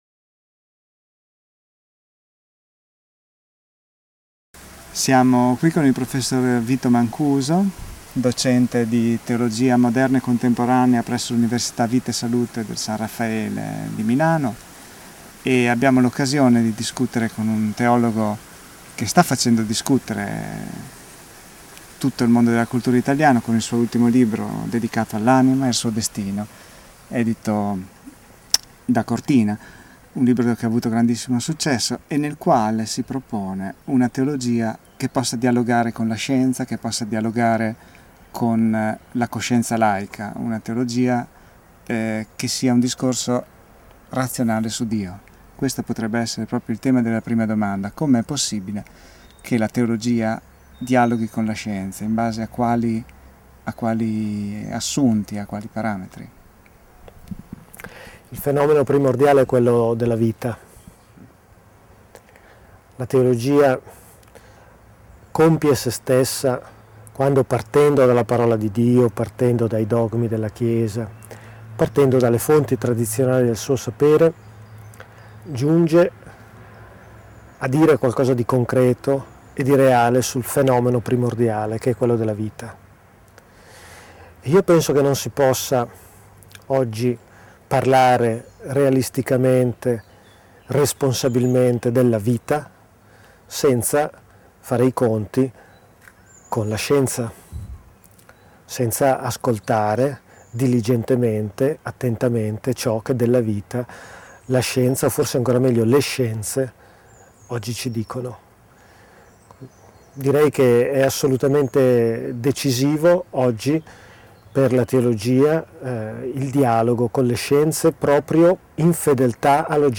Intervista a Vito Mancuso